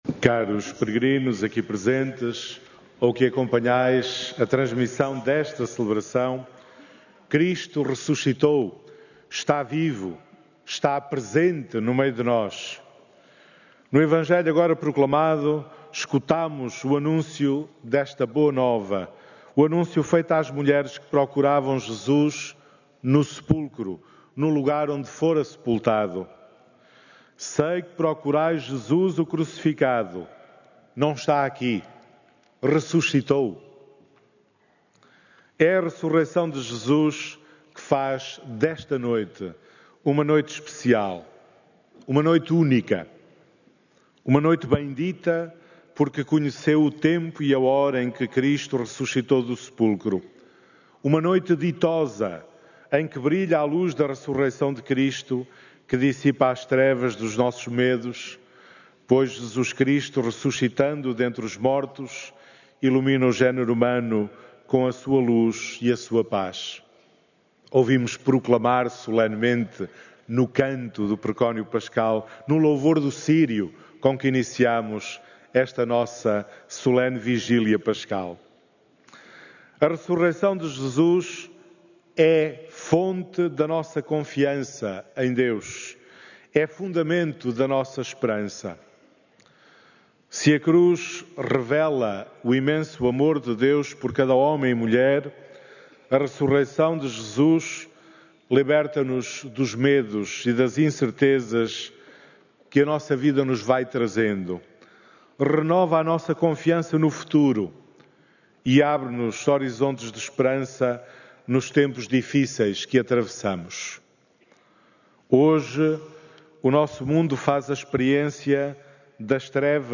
Na transição de Sábado Santo para o Domingo da Ressurreição do Senhor, os peregrinos ouviram, na Basílica da Santíssima Trindade, repetidos apelos à importância de um testemunho direto da Fé aos outros.
Áudio da homilia